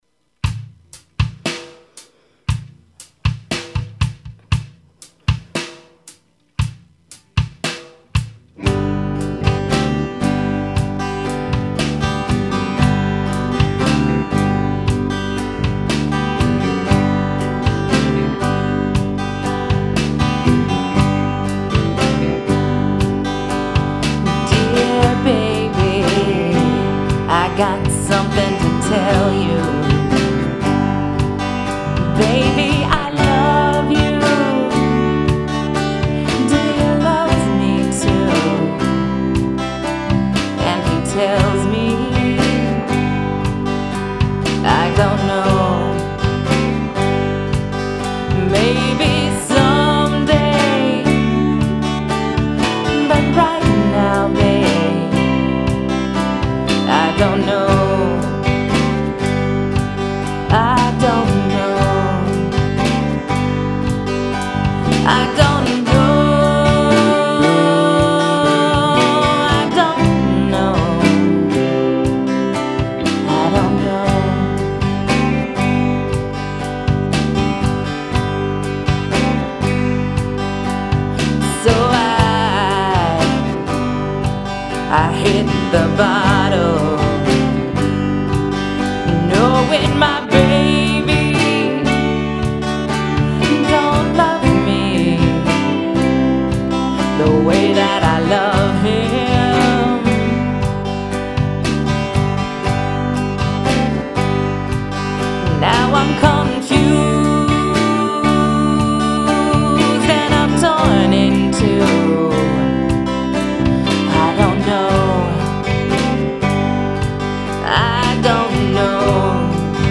two melancholy ballads about unrequited love
Guitar, Vocals
Drums
Bass
Lead Guitar